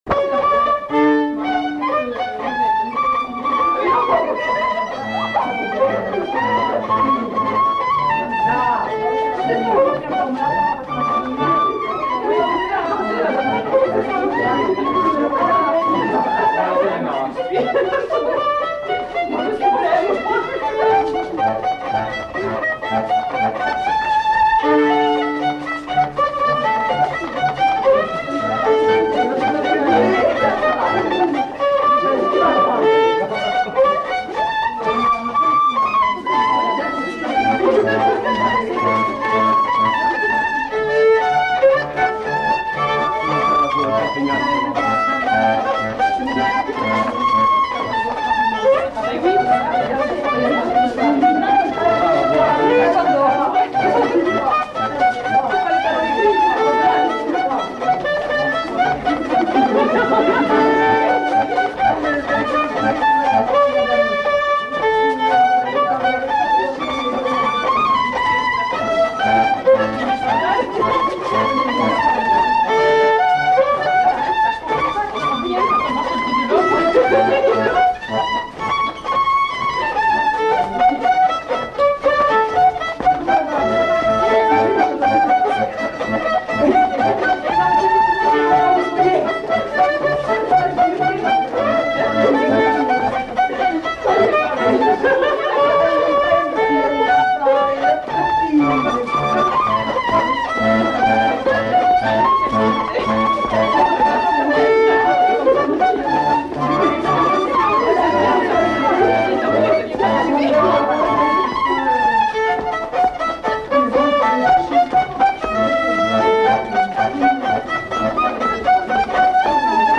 Lieu : Casteljaloux
Genre : morceau instrumental
Instrument de musique : violon
Danse : polka